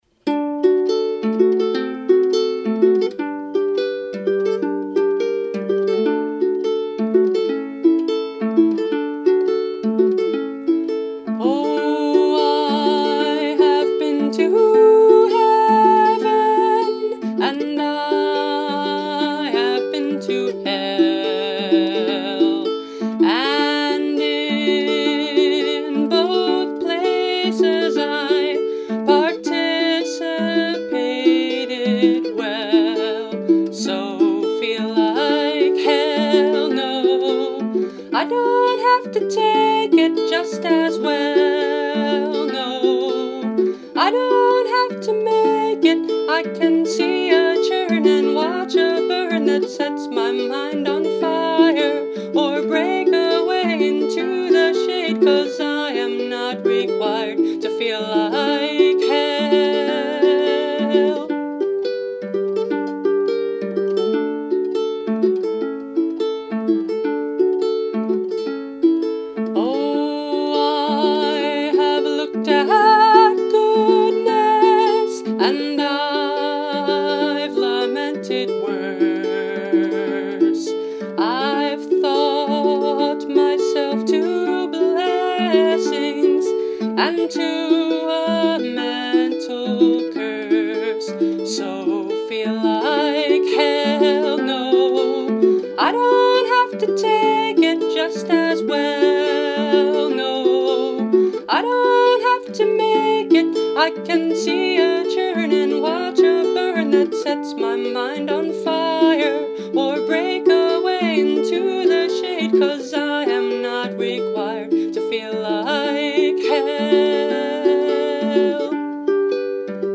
Instrument: Brio – Red Cedar Concert Ukulele